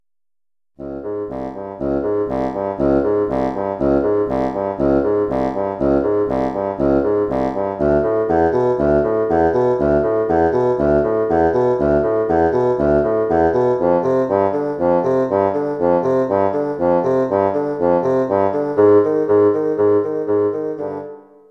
Bassons,   Basson 1,